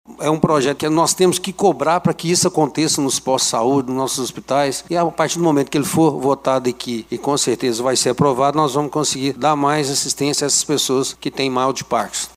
O autor do projeto é o vereador Marcílio de Souza, que ficou satisfeito com o apoio dos colegas. Segundo ele, a lei vai garantir um atendimento multiprofissional digno os pacientes.